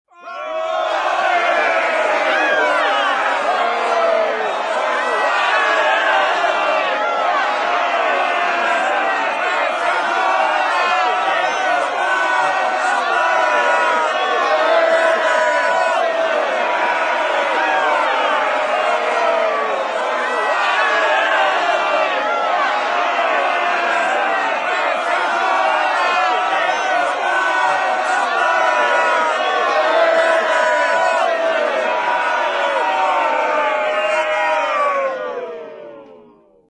Download Angry Crowd sound effect for free.
Angry Crowd